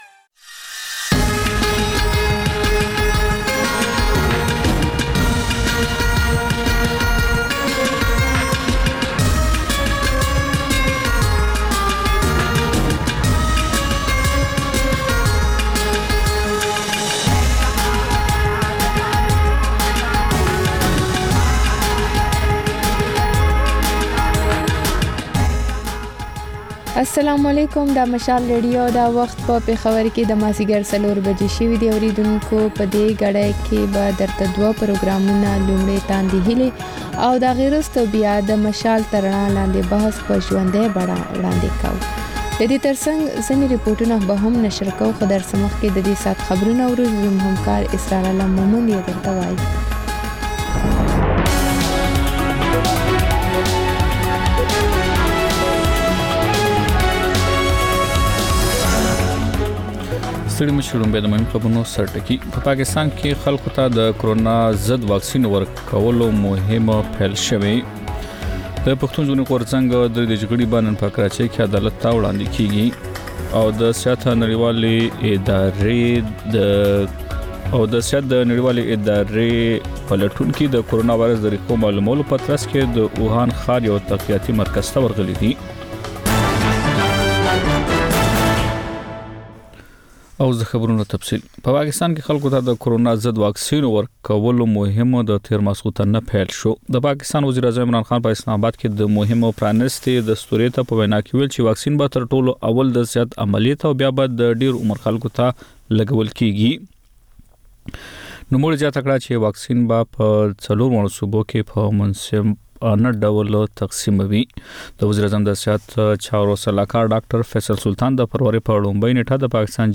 د مشال راډیو مازیګرنۍ خپرونه. د خپرونې پیل له خبرونو کېږي، بیا ورپسې رپورټونه خپرېږي. ورسره اوونیزه خپرونه/خپرونې هم خپرېږي.